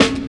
51 SNARE 3.wav